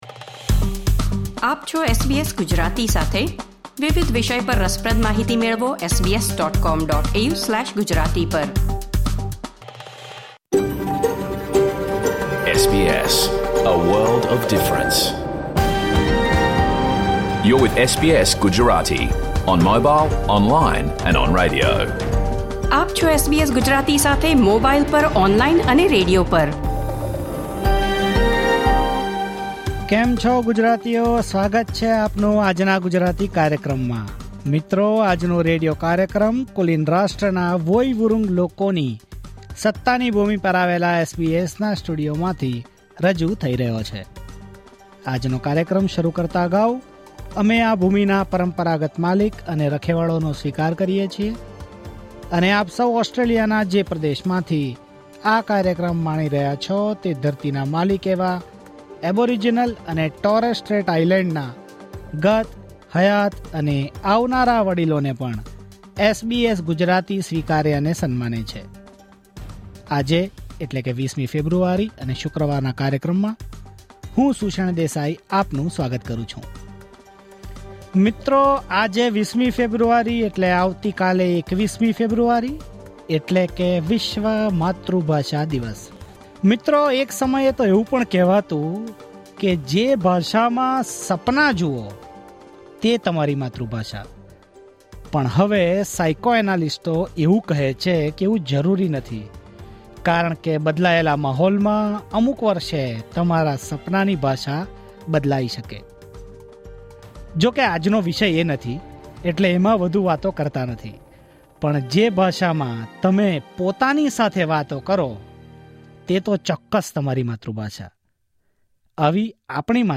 Listen to the full SBS Gujarati radio program